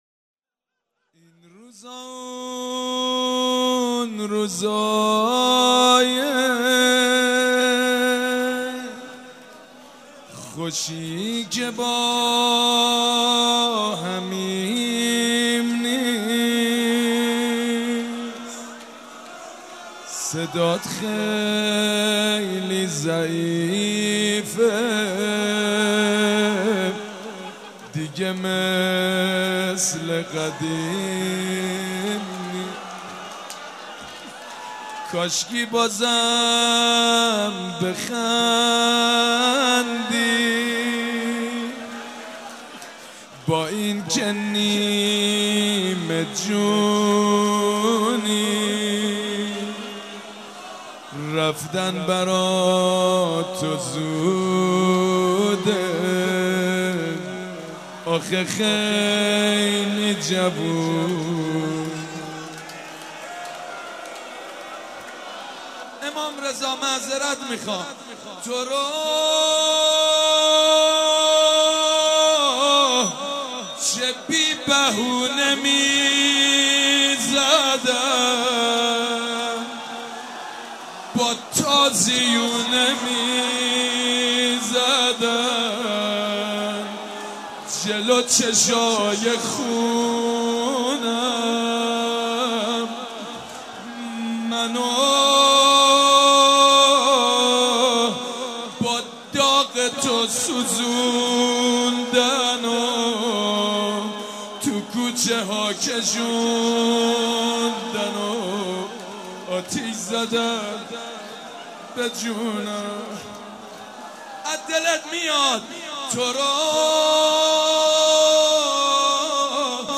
شب پنجم فاطميه دوم١٣٩٤ هيئت ريحانة الحسين(س)
روضه
مداح حاج سید مجید بنی فاطمه
roze.mp3